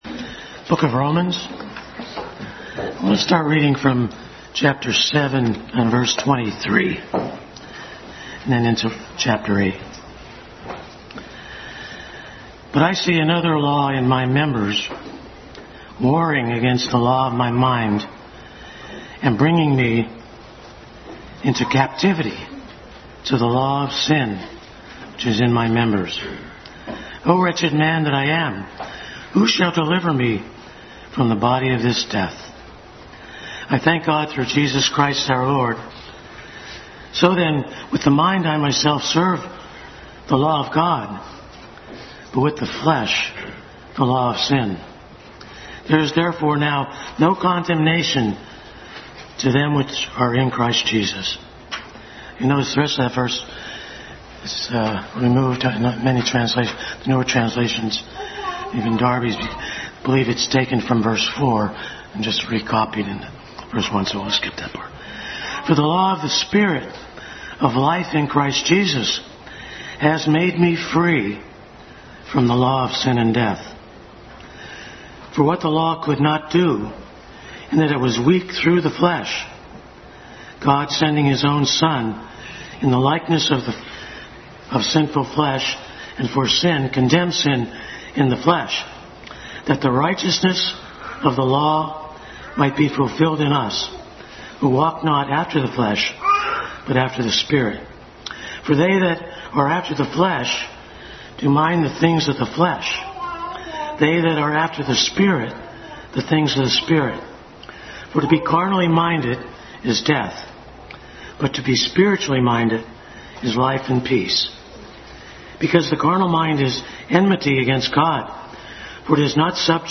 Adult Sunday School Class continued study in Romans.